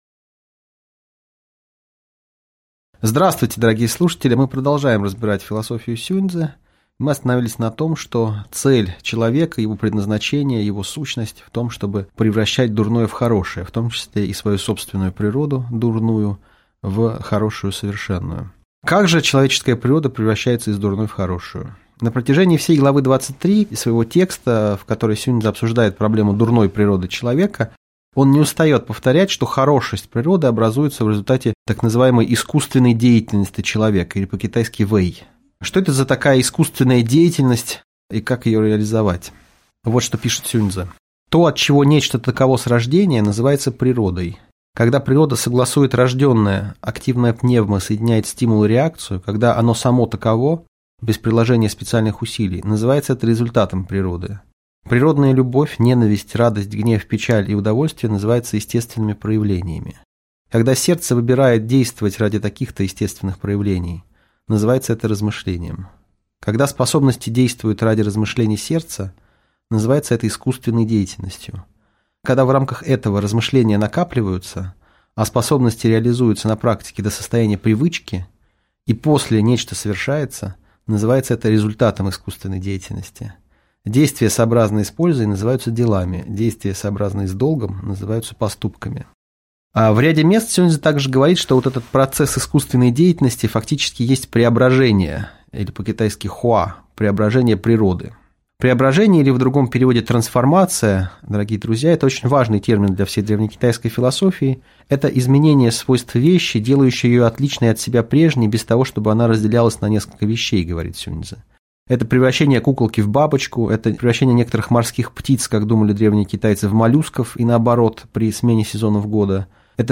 Аудиокнига Лекция «Сюнь-цзы. Часть II» | Библиотека аудиокниг